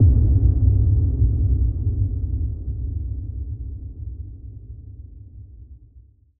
impact05.mp3